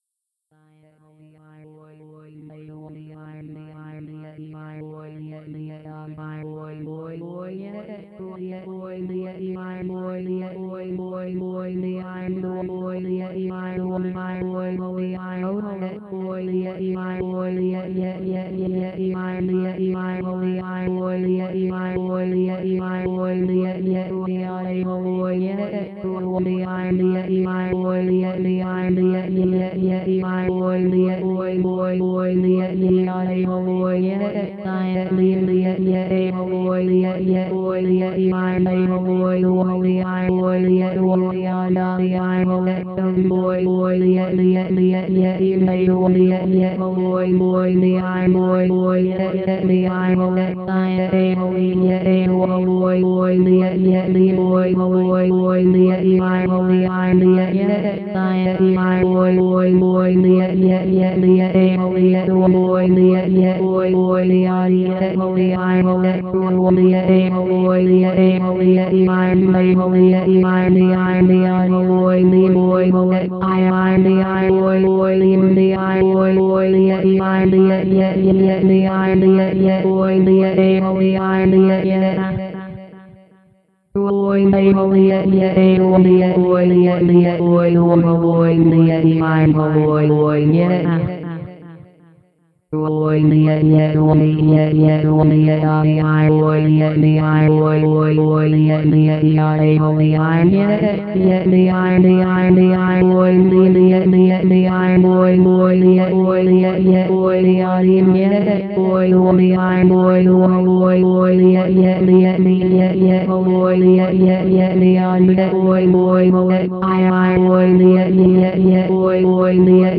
Sprachausgabe